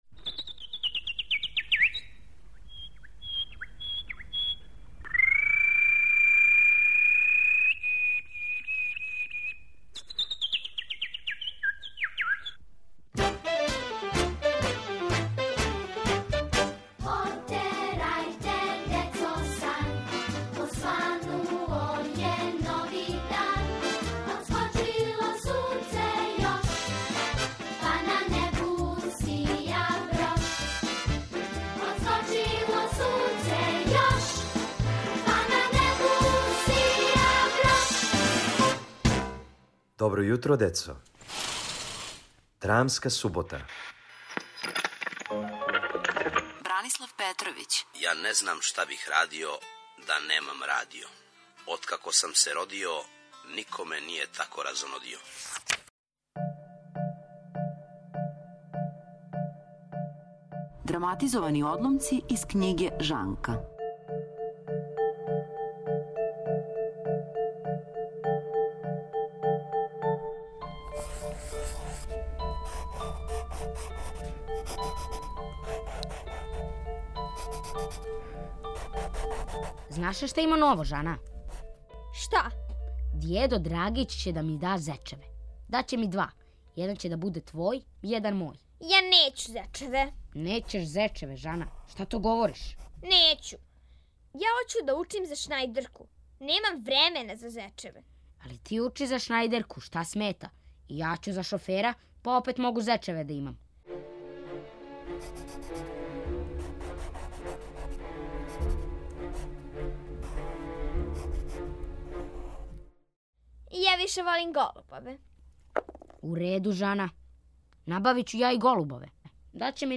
Будимо вас драматизованим одломцима из књиге Бране Петровића. Сазнајте шта овога пута смерају један дечак и девојчица Жана.